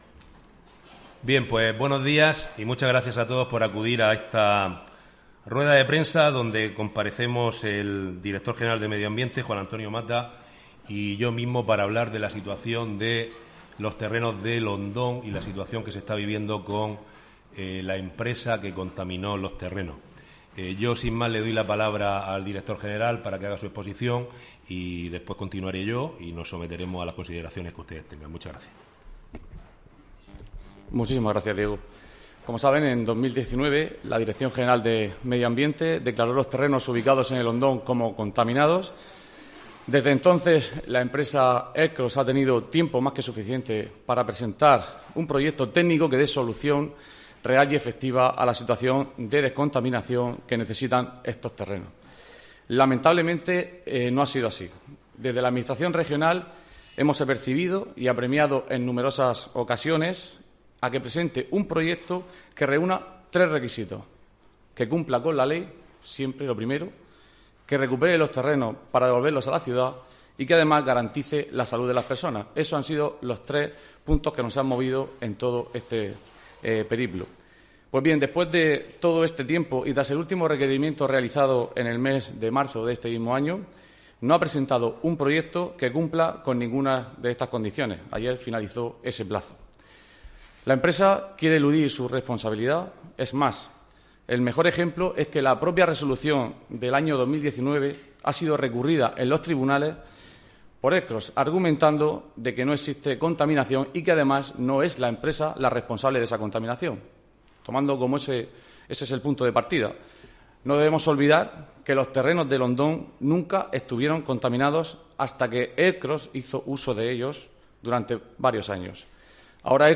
Enlace a Declaraciones del concejal Diego Ortega y el director general Juan Antonio Mata
El director general de Medio Ambiente, Juan Antonio Mata, y el segundo teniente de alcalde y concejal de Presidencia, Urbanismo e Infraestructuras, Diego Ortega, han anunciado en rueda de prensa que la Comunidad Autónoma ejecutará de forma subsidiaria la limpieza y recuperación ambiental de los terrenos de El Hondón, así como que se inicia un expediente sancionador contra la empresa, cuyo importe podría ser de hasta 570.000 euros.